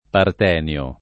part$nLo] s. m. (stor. «carme cantato da coro di fanciulle»; bot. «matricale»); pl. -ni (raro, alla lat., -nii) — sim. il top. m. P. (monte del Peloponneso, Gr.; massiccio del Montevergine, Camp.), il pers. m. stor. P. e il cogn. P.